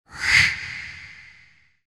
Supernatural breath sound - Eğitim Materyalleri - Slaytyerim Slaytlar